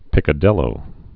(pĭkə-dĭlō, pēkä-dēyō)